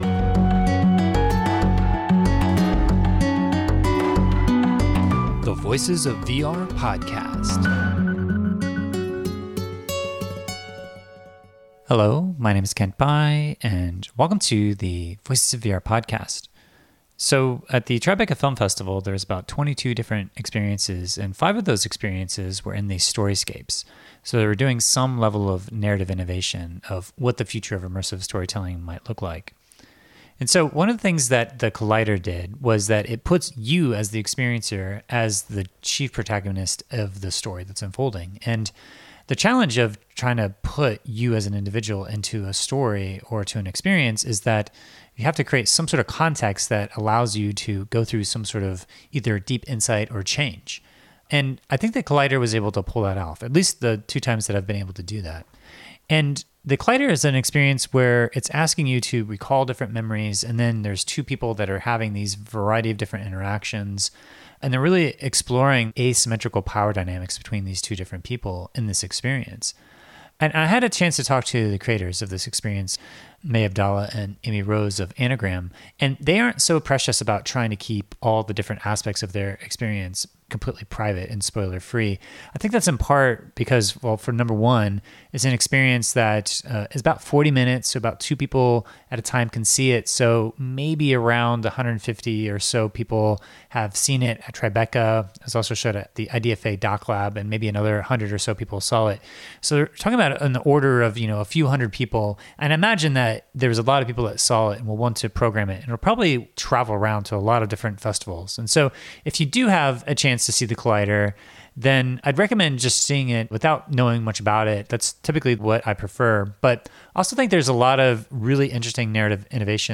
at the Tribeca Film Festival